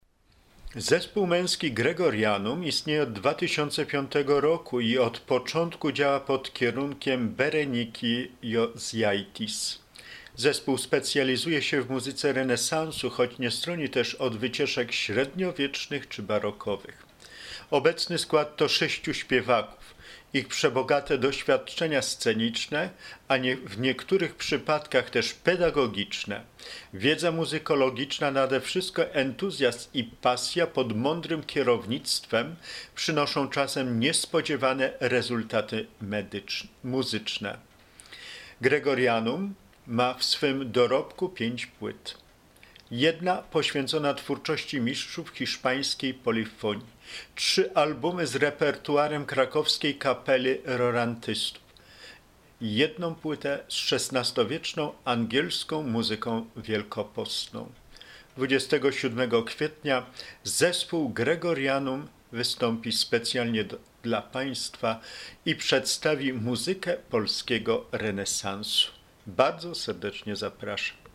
W programie: muzyka polskiego renesansu, fragmenty Dzienniczka św. s. Faustyny
Obecny skład to sześciu śpiewaków.